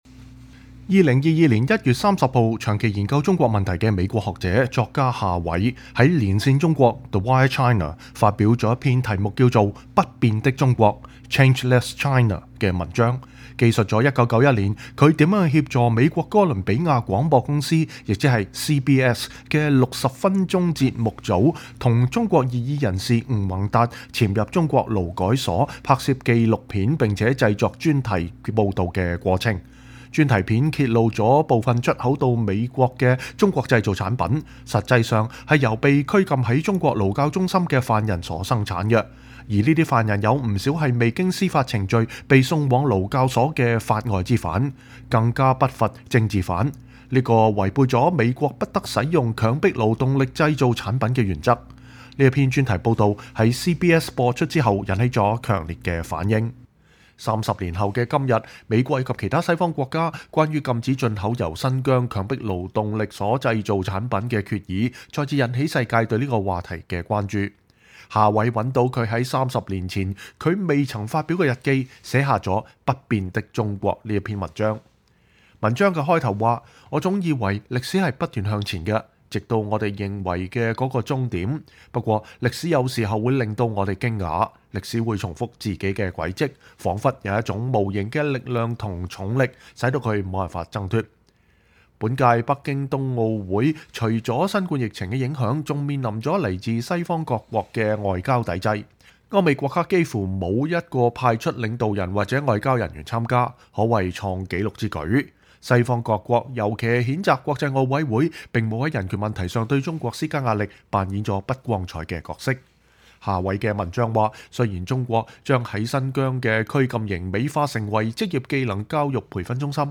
“不變的中國？” – 美國之音專訪學者夏偉(1)